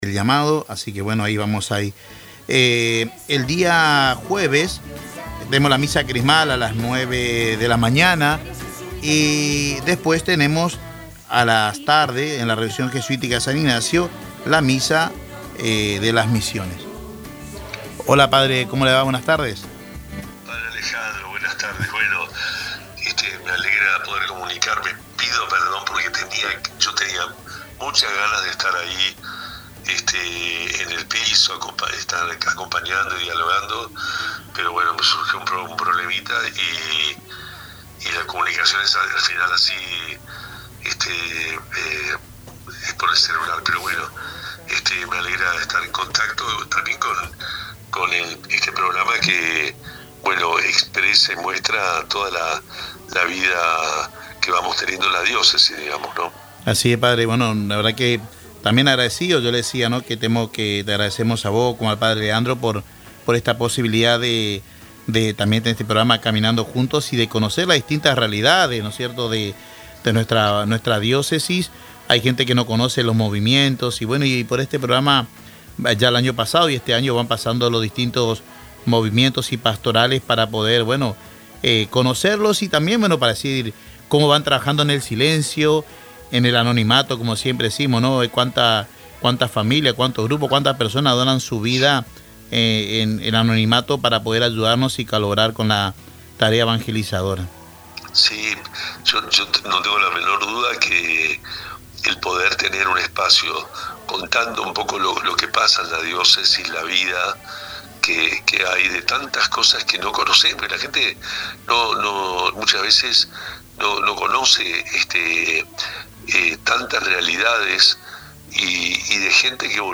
Este martes, en el programa Caminando juntos, transmitido por Radio Tupa Mbae, el Obispo de Posadas, monseñor Juan Rubén Martínez, reflexionó sobre diversos temas de actualidad en la Iglesia. Habló sobre las celebraciones litúrgicas de Semana Santa y los lugares donde presidirá las ceremonias, además de abordar el trabajo pastoral de la Iglesia en los barrios periféricos. También destacó el crecimiento de la matrícula en las escuelas católicas privadas, resaltando su impacto en la formación integral de niños y jóvenes.